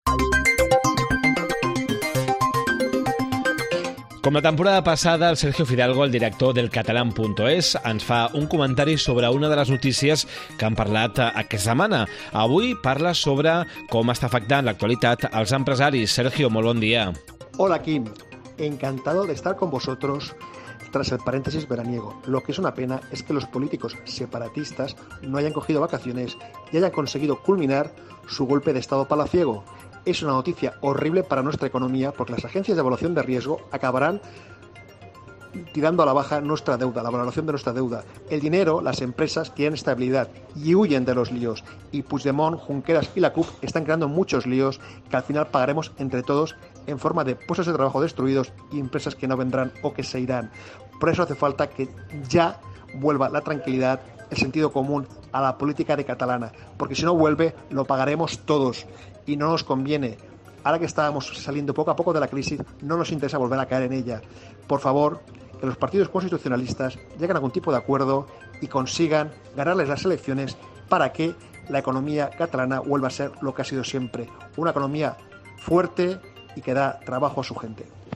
Comentari